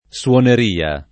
suoneria [ SU oner & a ]